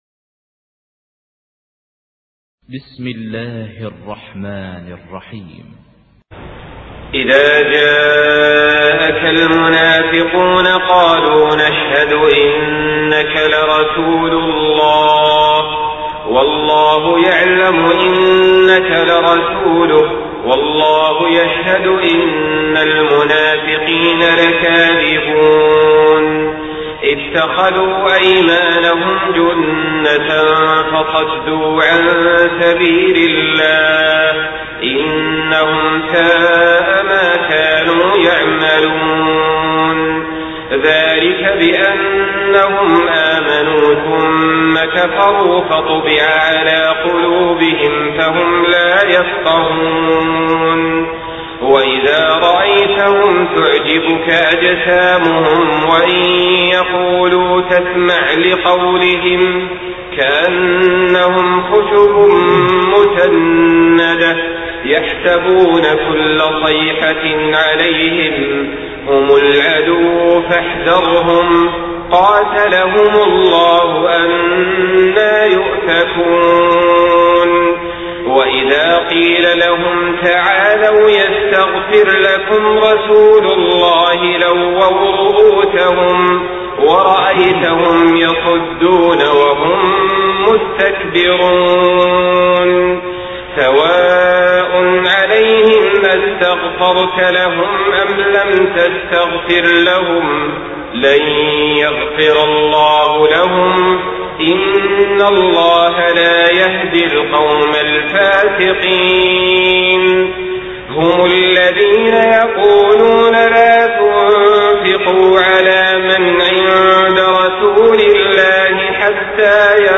Surah Münafikun MP3 by Saleh Al-Talib in Hafs An Asim narration.
Murattal Hafs An Asim